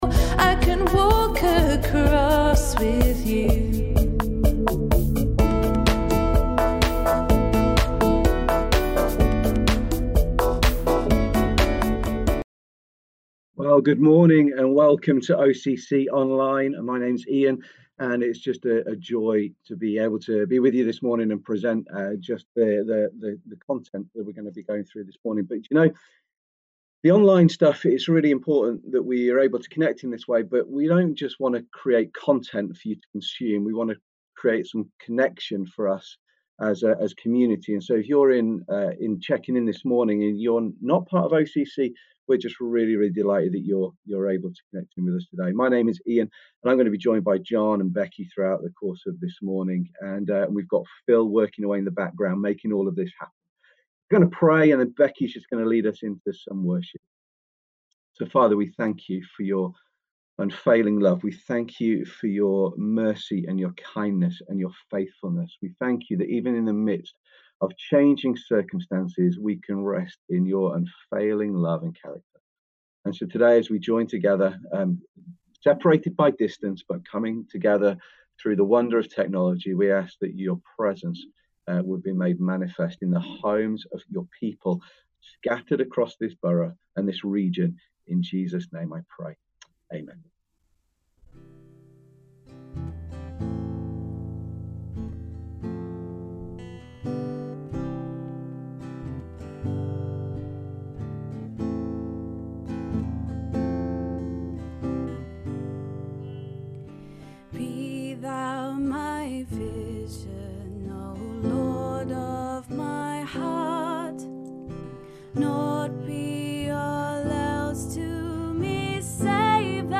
Listen again to our online gathering from Sunday.